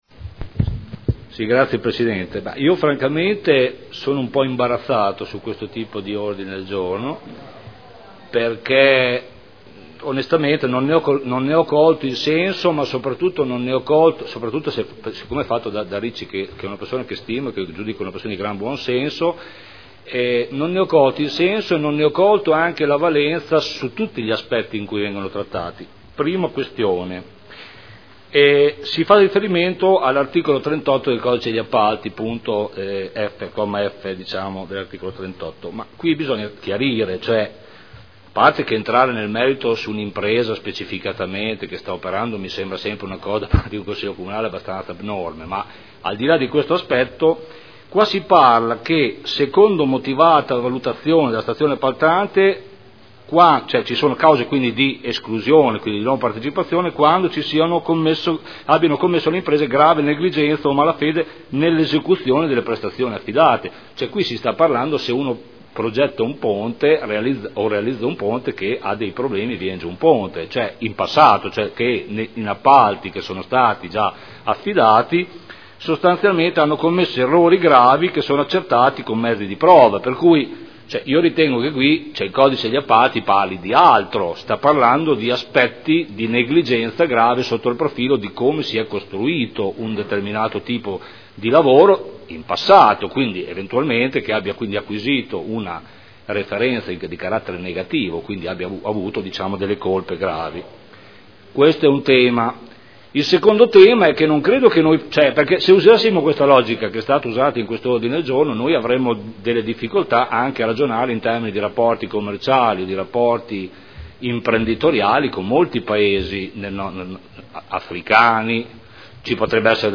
Giancarlo Campioli — Sito Audio Consiglio Comunale